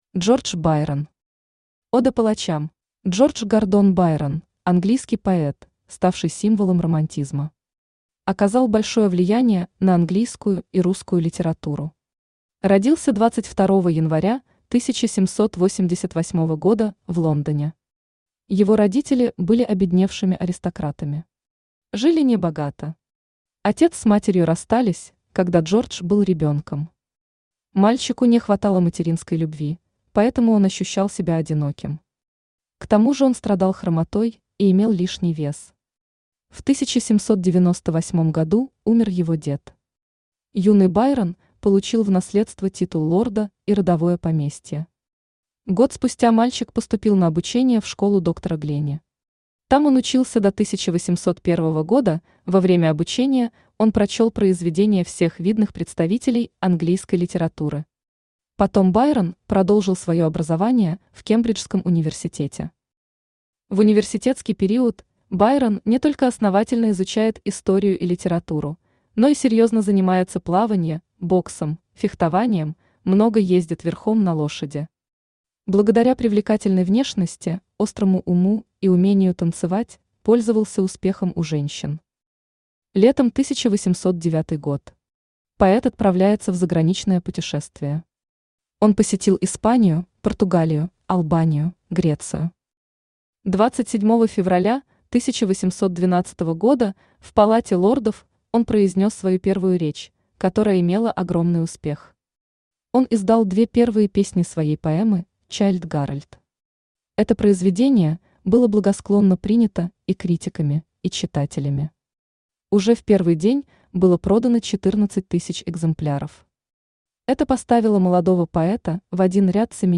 Aудиокнига Ода палачам Автор Джордж Гордон Байрон Читает аудиокнигу Авточтец ЛитРес.